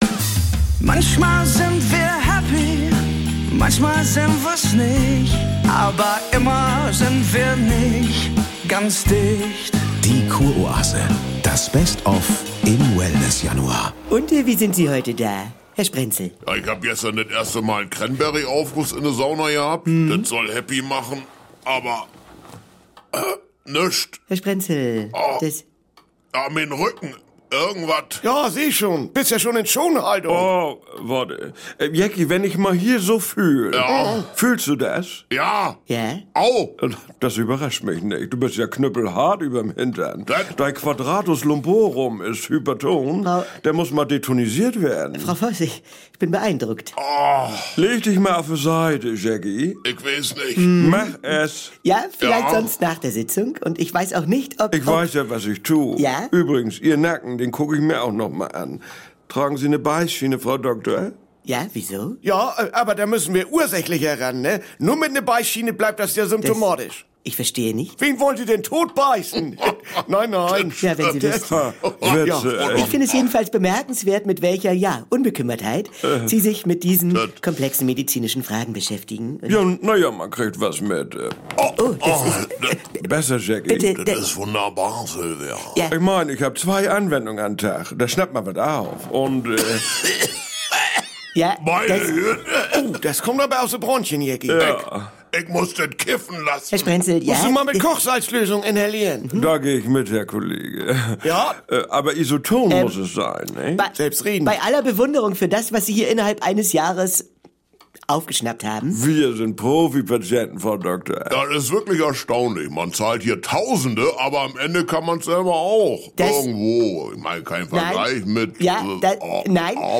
Kurz informiert liefert täglich die wichtigsten Nachrichten zu IT, Mobilem, Gadgets, Netzpolitik & Wissenschaft – heute mit der synthetischen Stimme